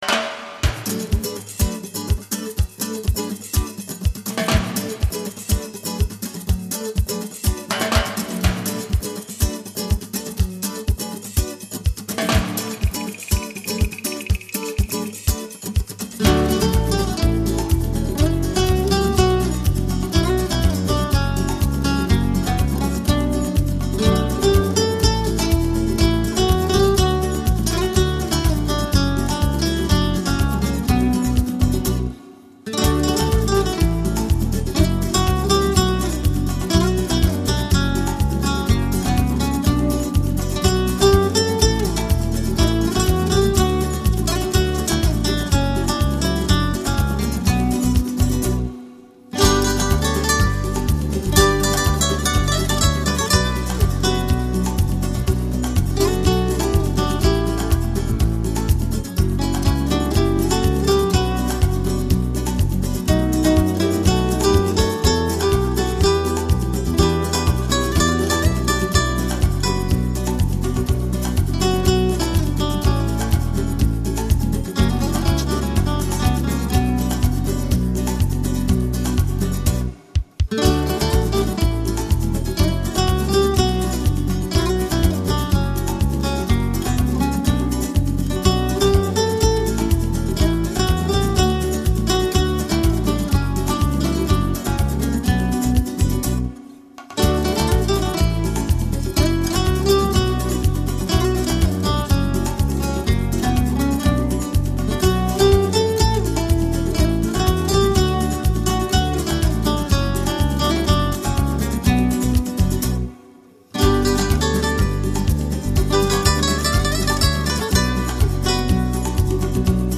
在无忧无虑的热带敲击节奏的烘托下，轻快流畅的旋律从指尖流淌出来
但是吉他声音也录得通透、清澈、传真和富有空气感，把乐意表现得细致入微、丝丝入扣。